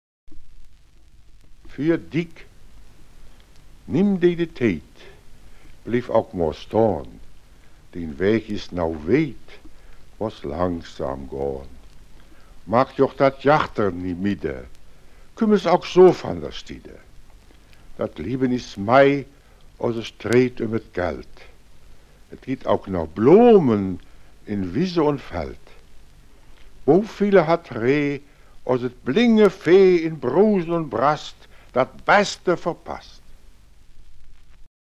in "Rhodener Mundart"